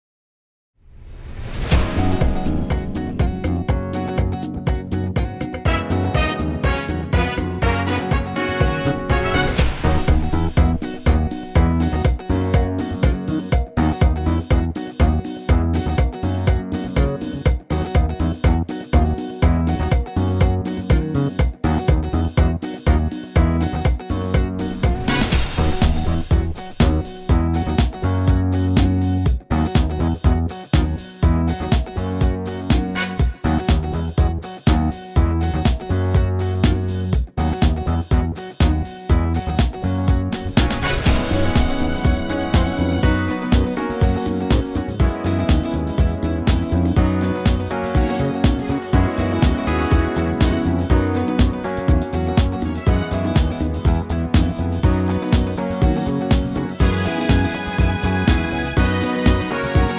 key - B - vocal range - D# to F#
Suitable for high male ranges and lower female.